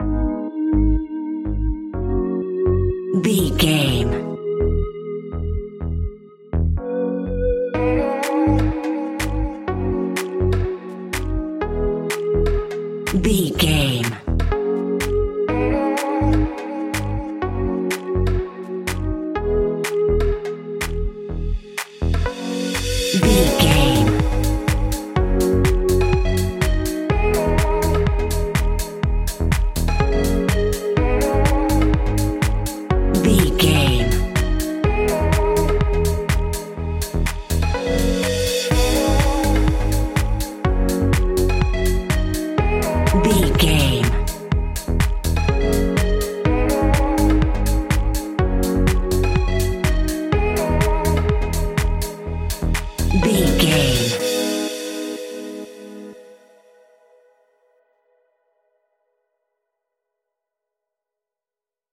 Aeolian/Minor
G#
groovy
uplifting
futuristic
energetic
happy
repetitive
drum machine
synthesiser
piano
electro house
funky house
synth leads
synth bass